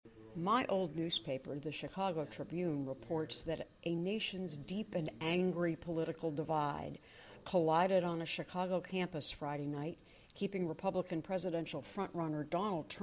A report from the Chicago Tribune